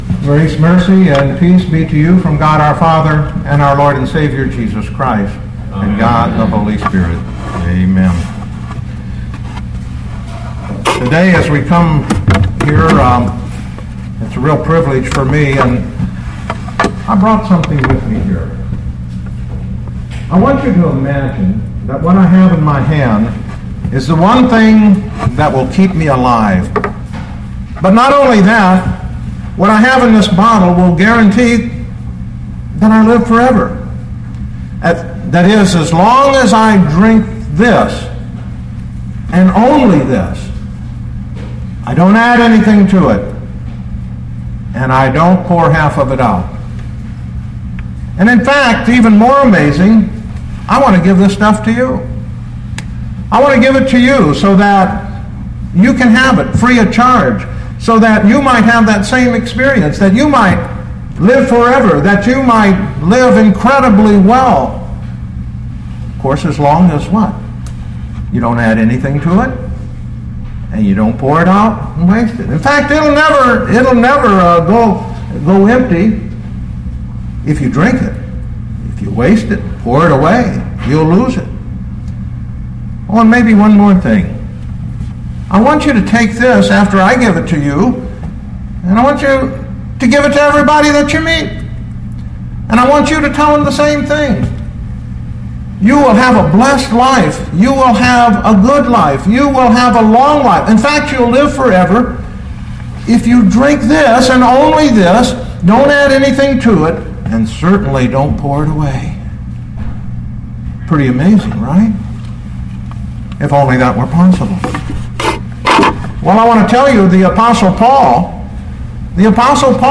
2013 Colossians 2:6-15 Listen to the sermon with the player below, or, download the audio.